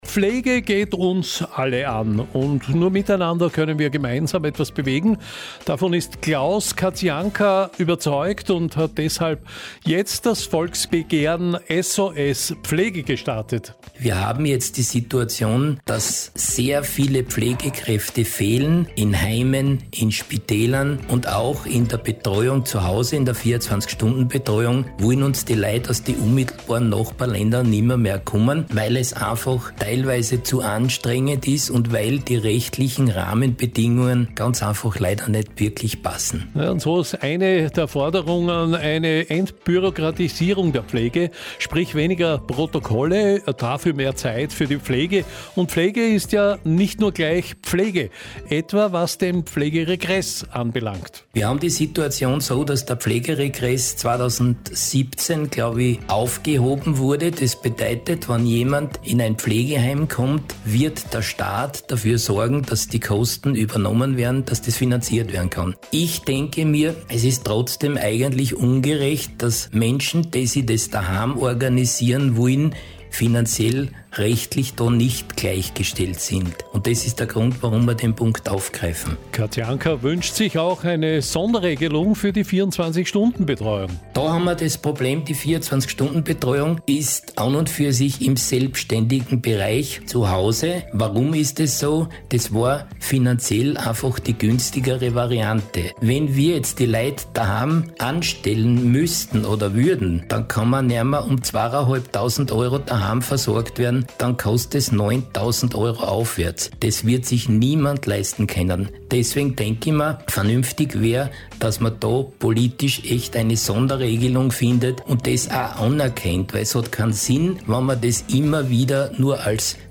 PK Volksbegehren SOS PFLEGE!